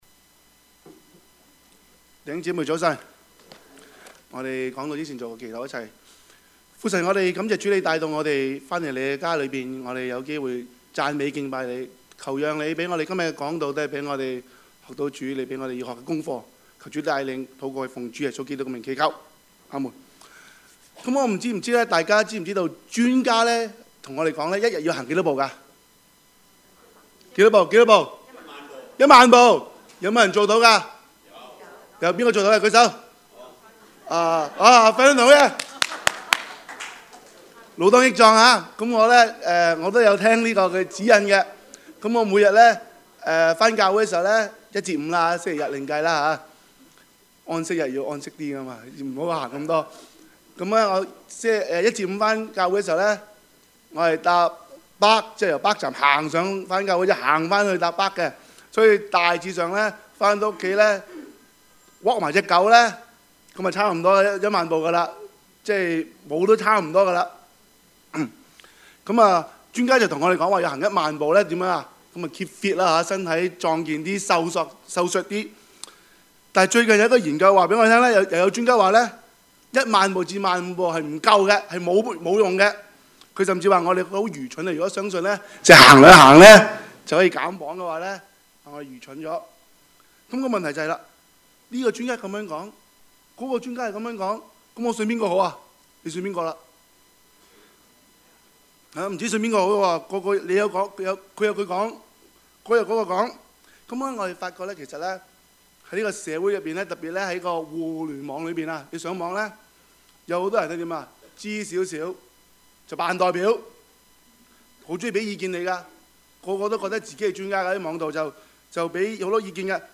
Service Type: 主日崇拜
Topics: 主日證道 « 美麗島事件 空的墳墓 »